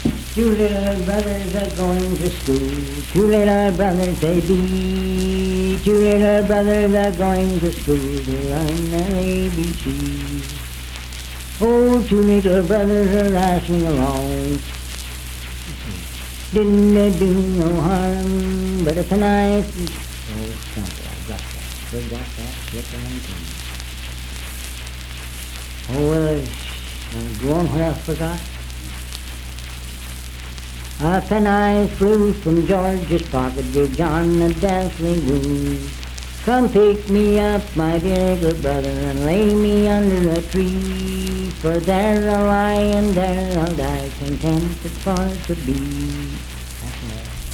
Unaccompanied vocal music
Performed in Sandyville, Jackson County, WV.
Voice (sung)